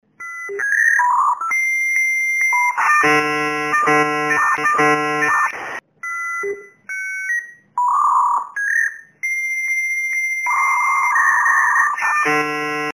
Здесь вы можете слушать и скачивать знаменитые сигналы dial-up соединения: от начального гудка до узнаваемых помех.
Старый модем